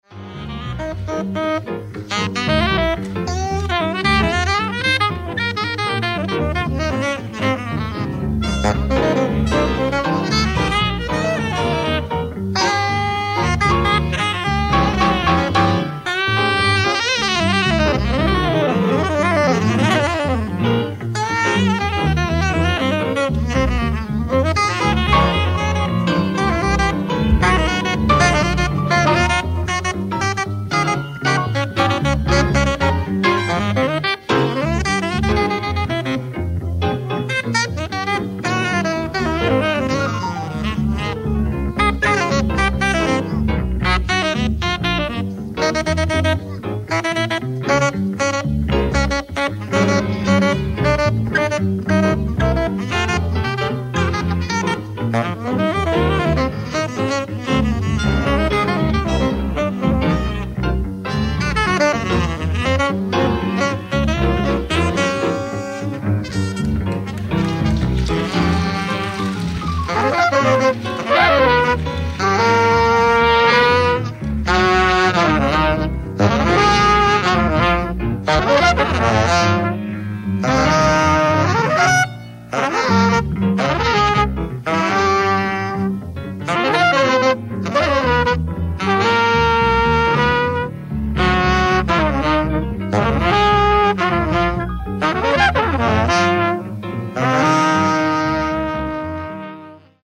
ライブ・アット・ミシガン・ユニオンボールルーム、ミシガン大学、アン・アーバー、ミシガン 02/04+05/1977
※試聴用に実際より音質を落としています。